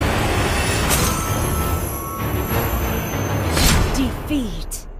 MLBB defeat soundboard meme, dramatic game over audio used for fails, losses, salty reactions, and funny edits.